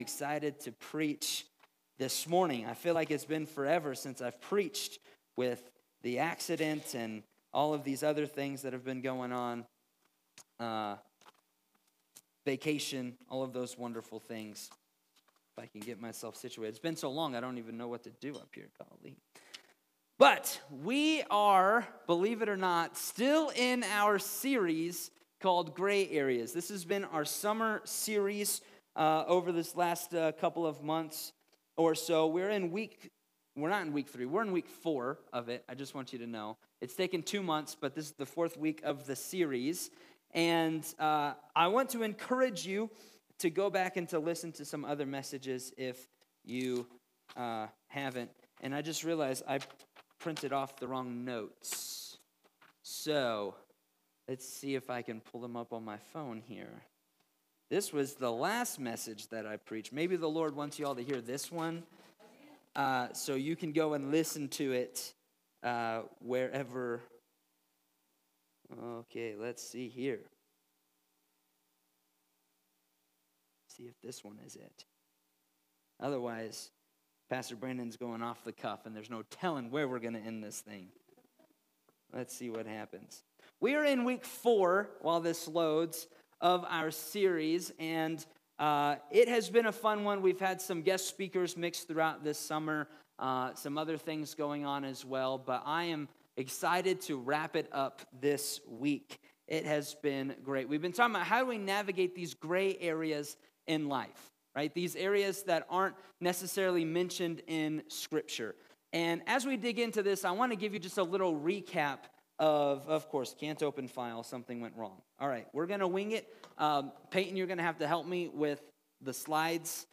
Download Download Reference Romans 14:13-23 Sermon Notes 4.